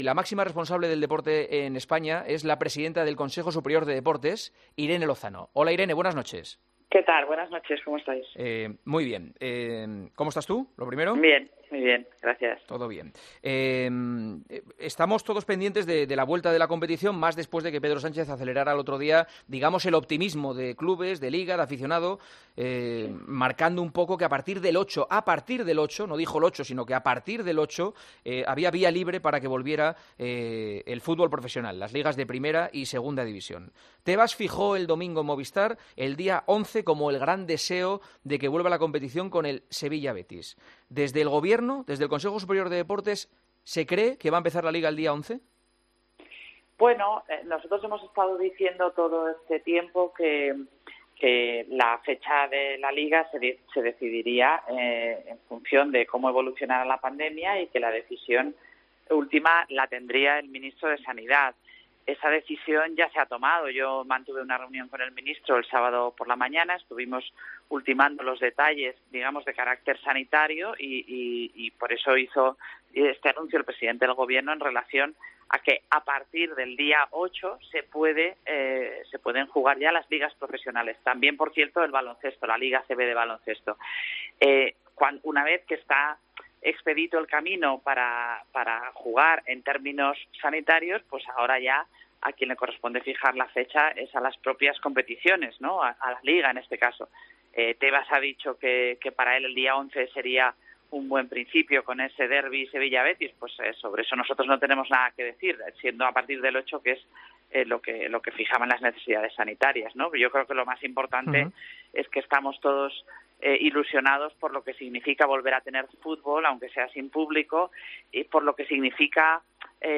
AUDIO - ENTREVISTA A IRENE LOZANO, PRESIDENTA DEL CSD, EN EL PARTIDAZO DE COPE La presidenta del Consejo Superior de Deportes, Irene Lozano, mostró su optimismo respecto a la vuelta del fútbol con público, en El Partidazo de COPE.